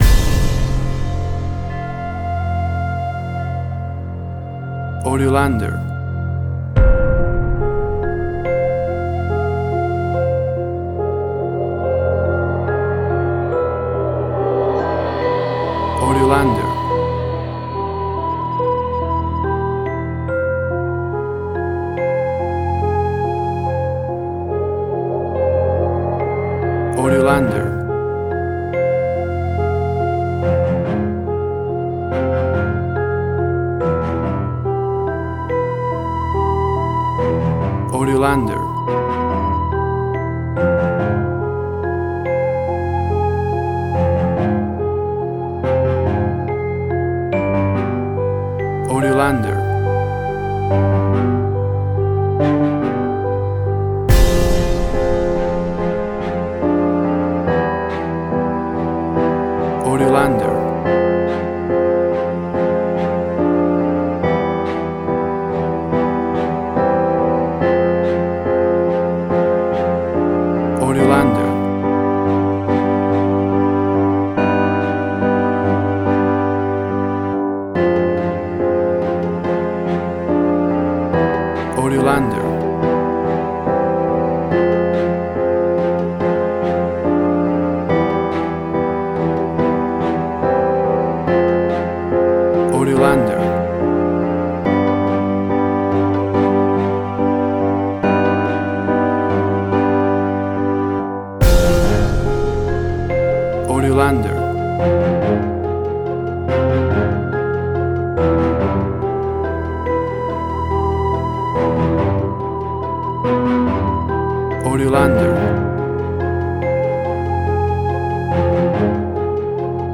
Suspense, Drama, Quirky, Emotional.
Tempo (BPM): 71